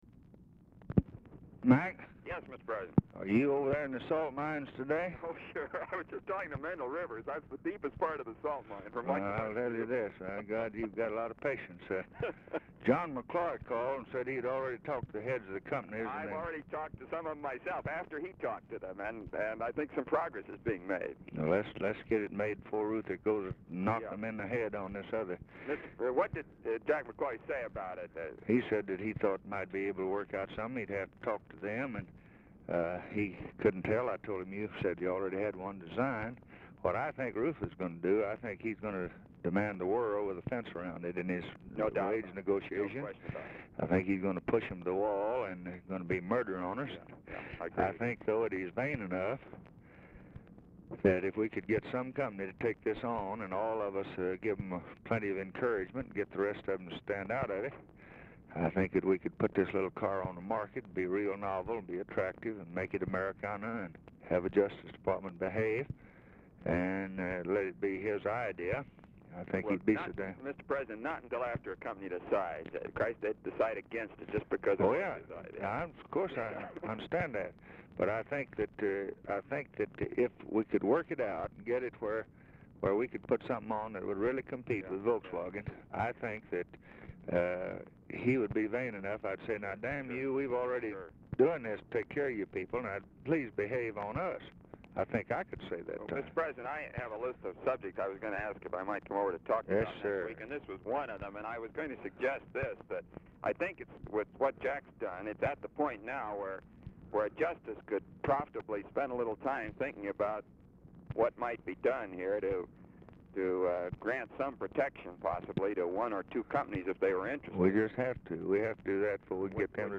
Telephone conversation # 1535, sound recording, LBJ and ROBERT MCNAMARA, 1/25/1964, 11:50AM
Format Dictation belt
Location Of Speaker 1 Oval Office or unknown location